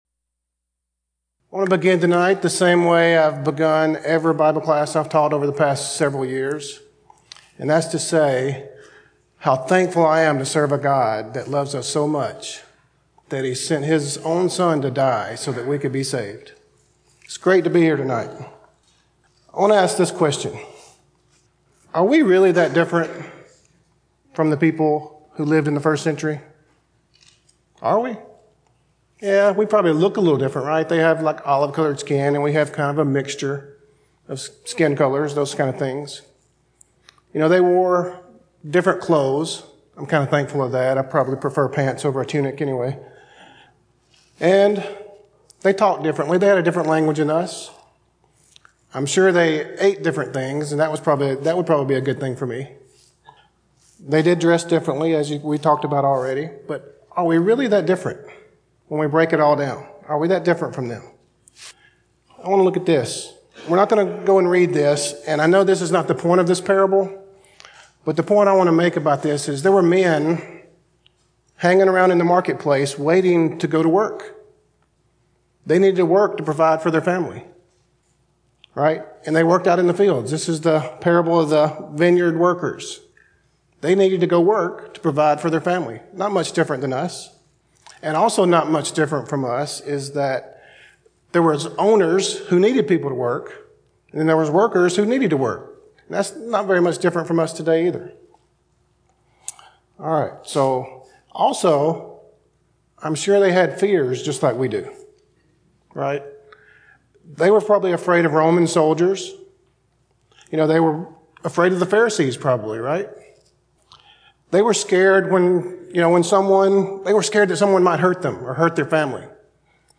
General Service: Sun PM Type: Sermon Speaker